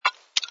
sfx_slurp_bottle08.wav